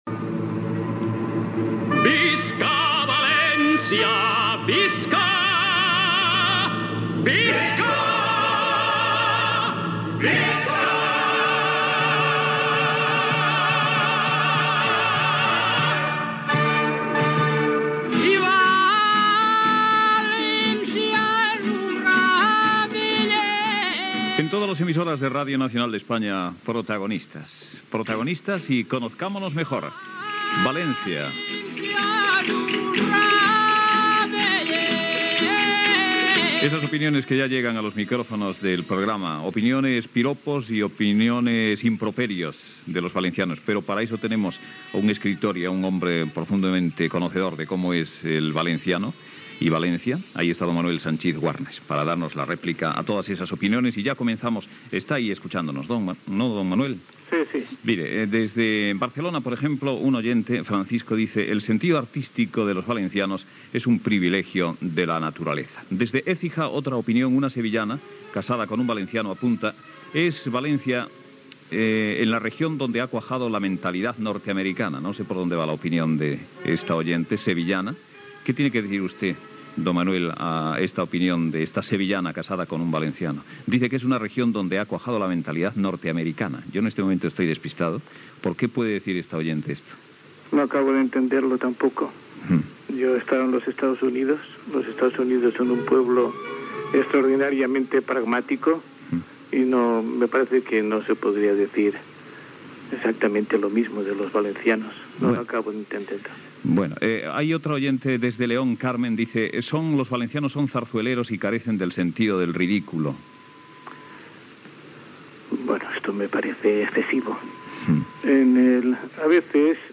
Identificació del programa. "Conozcámonos mejor": opinions dels oïdors sobre els valencians i participació de l'historiador valencià Manuel Sanchís Guarner
Info-entreteniment